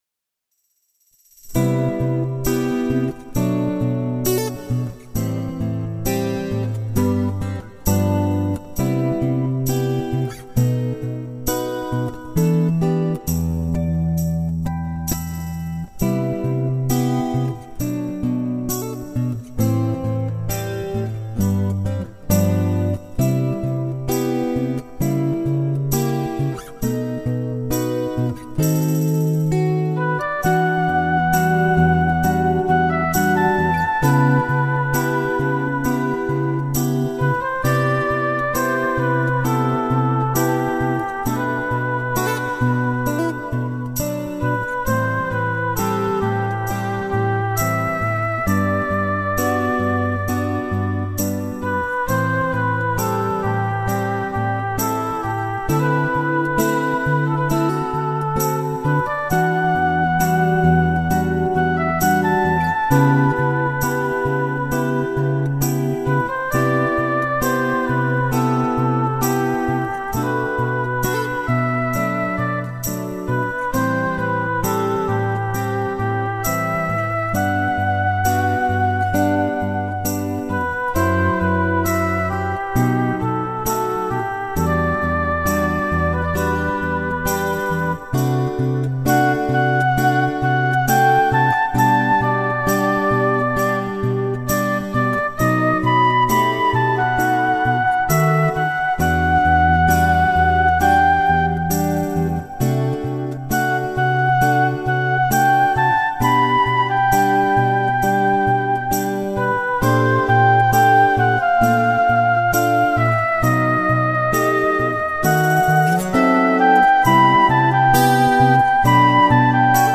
2008年　1月・・・アコギとフルートのデュオ的なシンプルなものです。
”せつな系”っすね〜ど〜も自分はこれ系が好きっぽい〜？？（苦笑）